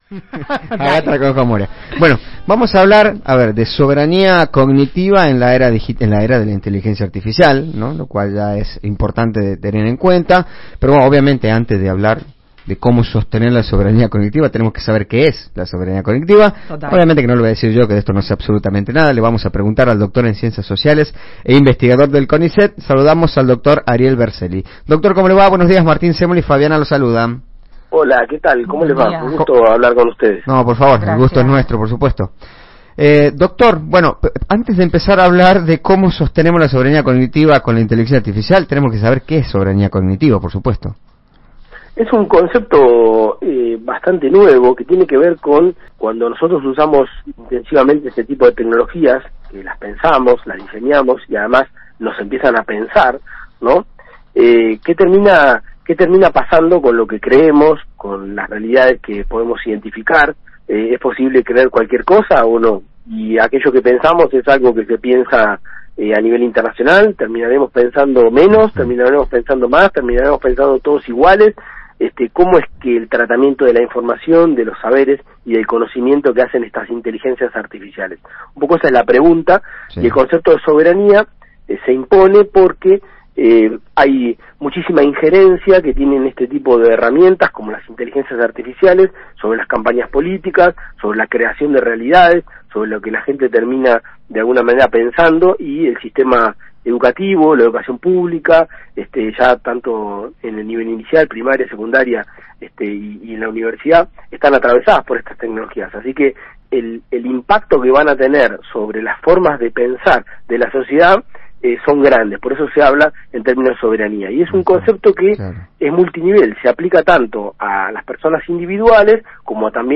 [Entrevista en Radio LV12 Tucumán] Soberanía cognitiva en la era digital: ¿Hay riesgos con la IA?